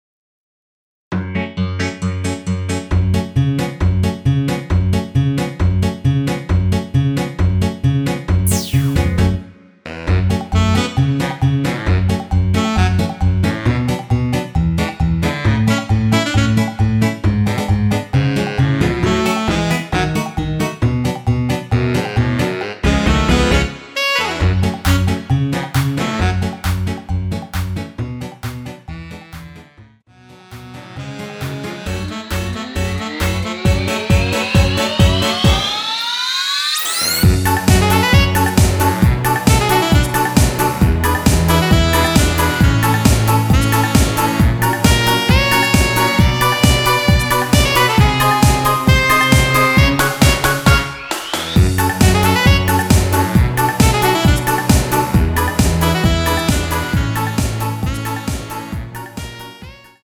F#m
앞부분30초, 뒷부분30초씩 편집해서 올려 드리고 있습니다.
중간에 음이 끈어지고 다시 나오는 이유는